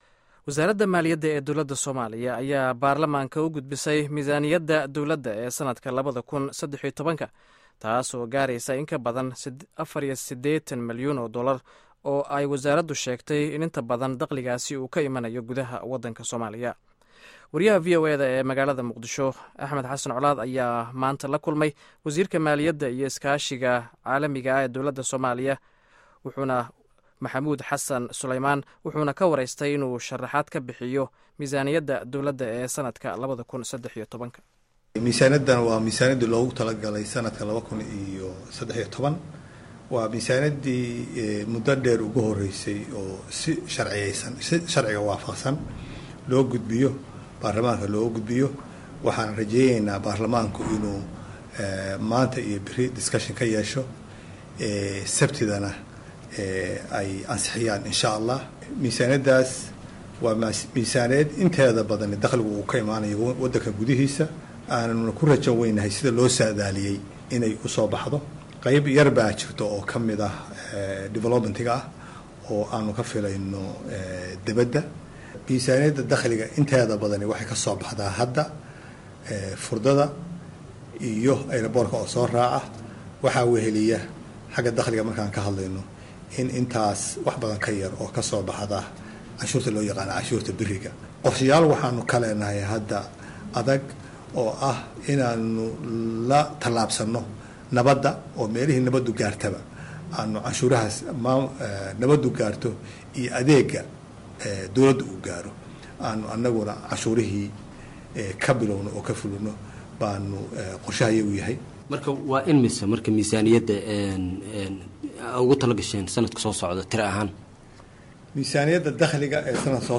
Wareysiga Wasiirka Maaliyadda Somalia